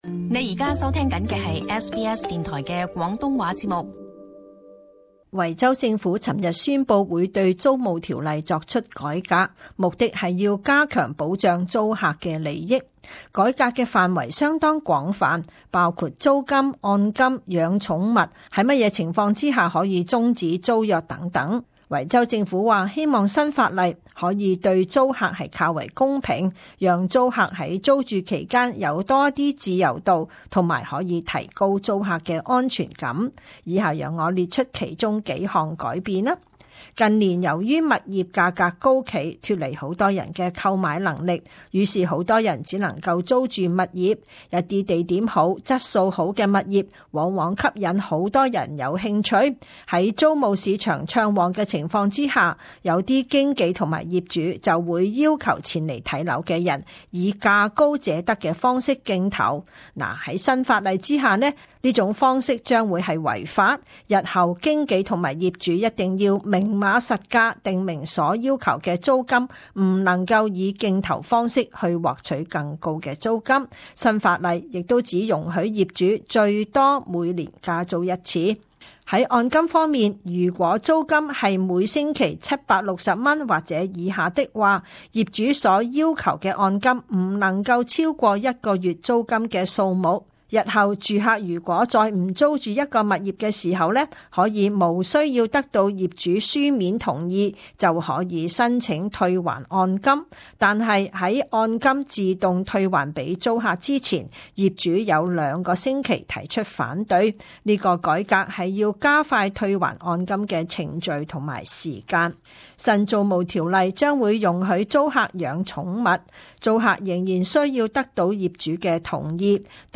【時事報導】維州行將修改租務條例